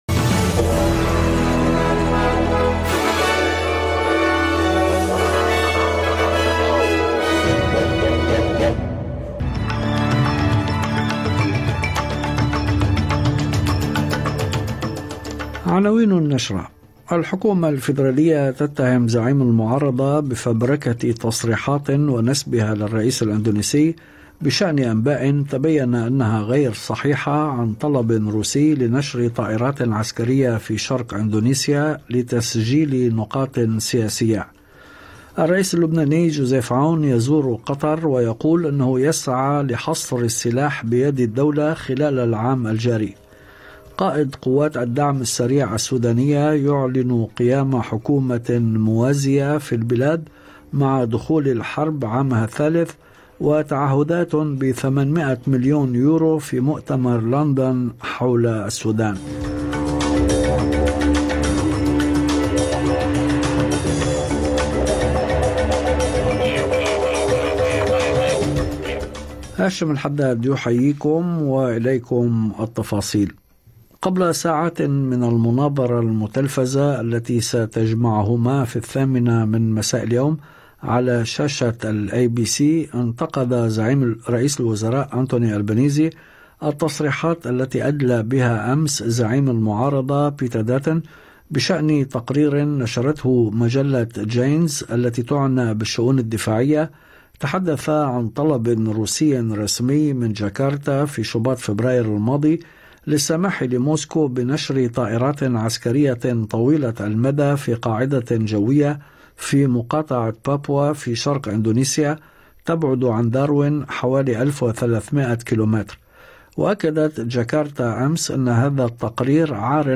نشرة أخبار المساء 16/04/2025